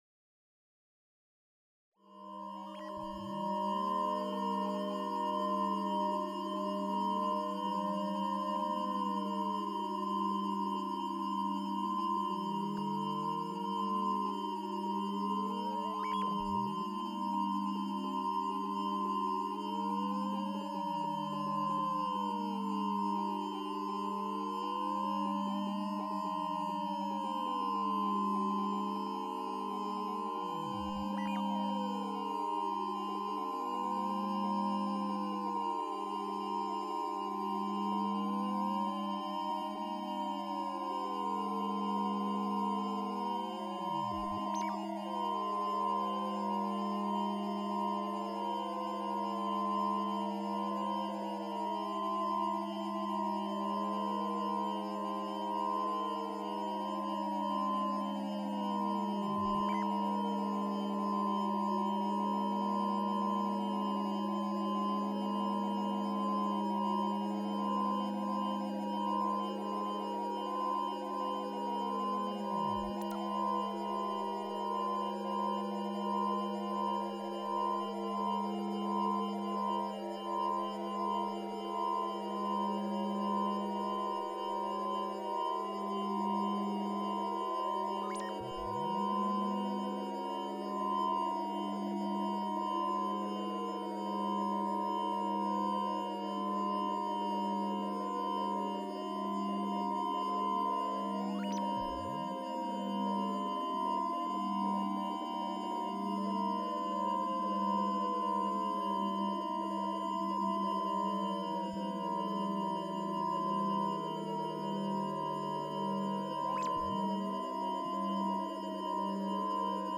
Daily Sound Installation With User Interface - Musical Coffee Time
This project was conducted by using the facilities provided by the Electronics Laboratory and the MAT workshop of QMUL.
In this project I envisioned a scenario that coffee enthusiasts experience daily, utilizing interactive technologies to make the movement of Moka Pot soundable.
musical-coffee-time.m4a